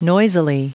Prononciation du mot noisily en anglais (fichier audio)
Prononciation du mot : noisily